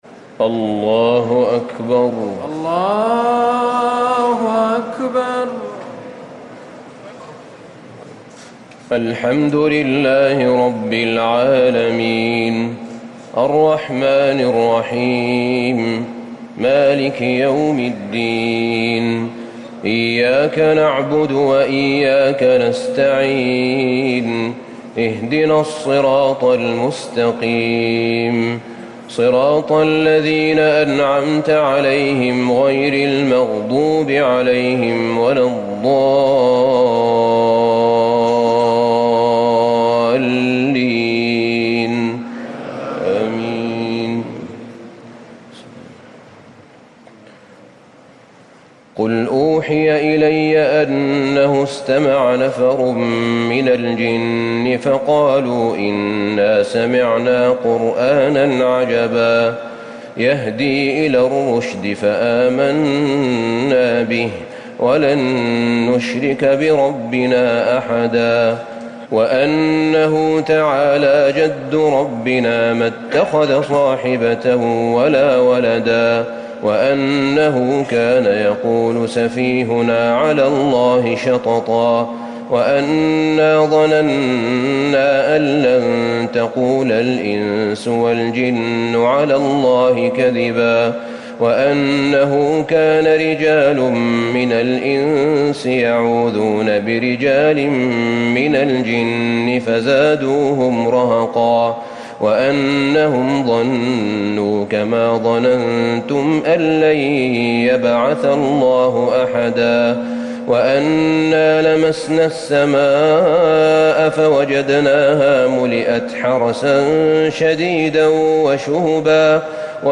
تراويح ٢٨ رمضان ١٤٤٠ من سورة الجن - المرسلات > تراويح الحرم النبوي عام 1440 🕌 > التراويح - تلاوات الحرمين